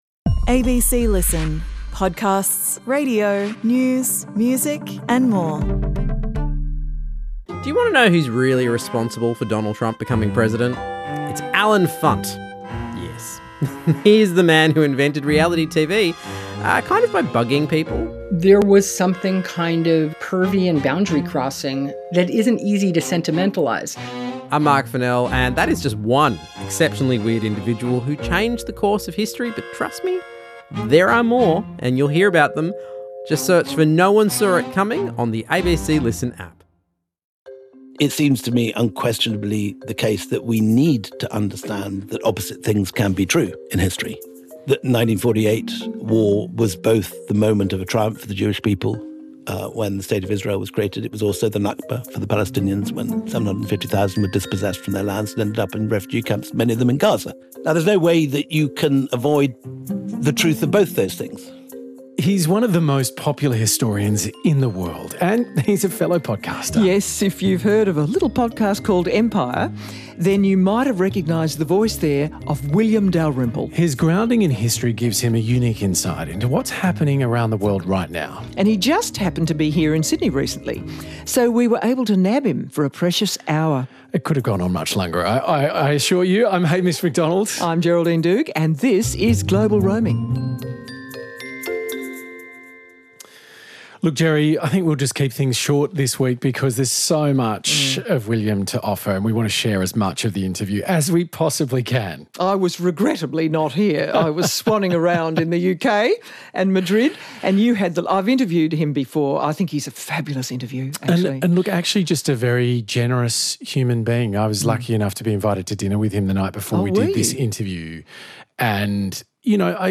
In a broad-ranging conversation, Hamish Macdonald speaks to historian and host of the hit podcast Empire William Dalrymple about the pitfalls of trying to understand the contemporary world without a firm grasp of the past, whether India can replicate its success as an ancient superpower and how countries like Australia and Britain deal with uncomfortable truths from their past.